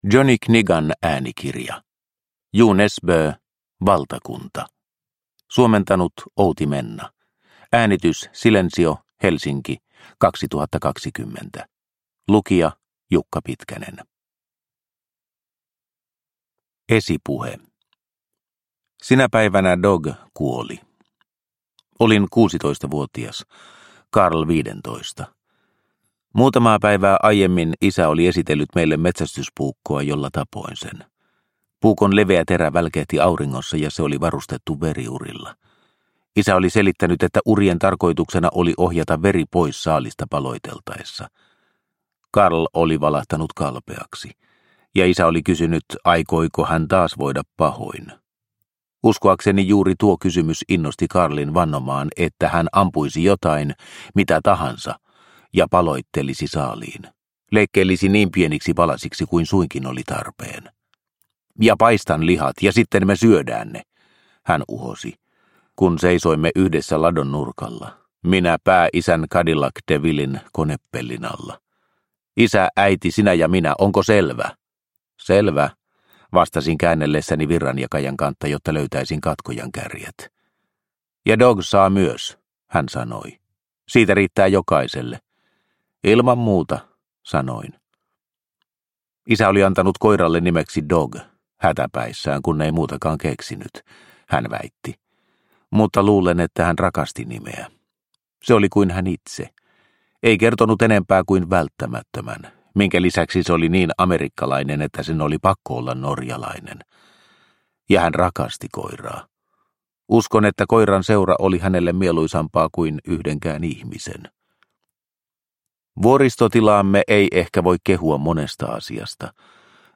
Valtakunta – Ljudbok – Laddas ner